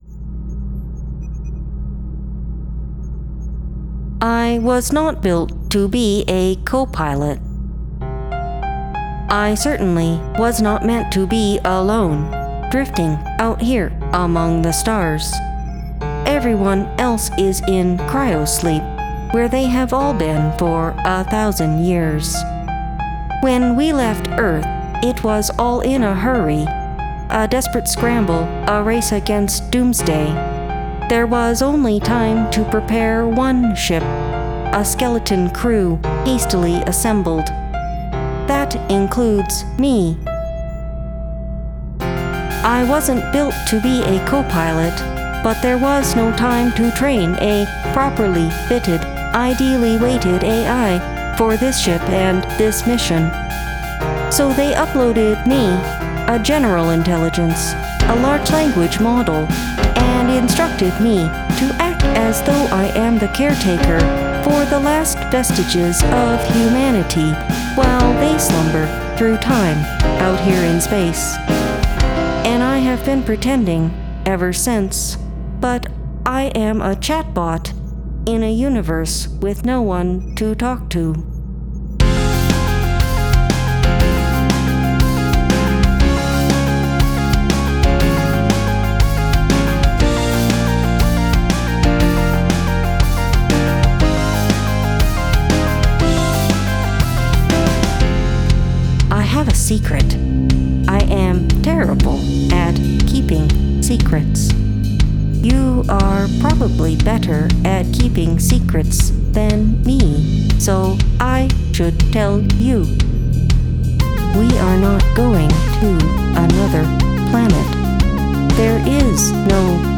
Guest Lead Vocals
It’s a damn shame we don’t get any of your vocals.